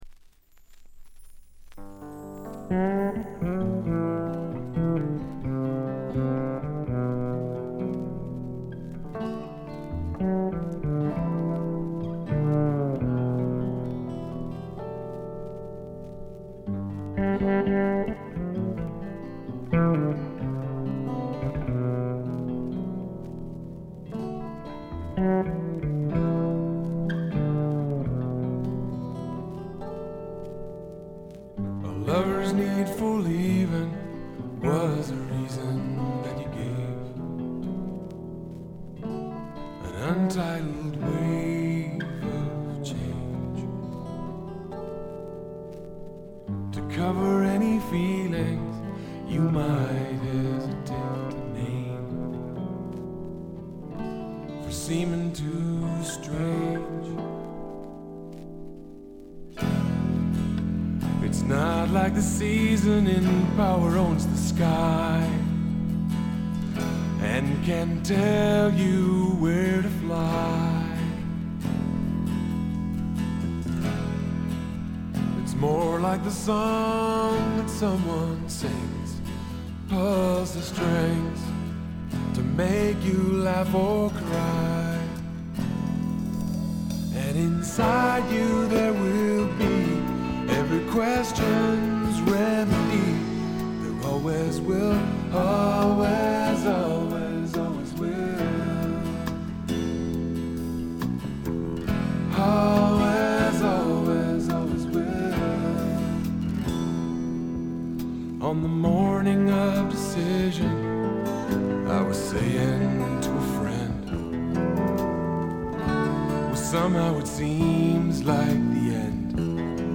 静音部で軽微なチリプチ。
メロウ系、AOR系シンガー・ソングライターのずばり名作！
試聴曲は現品からの取り込み音源です。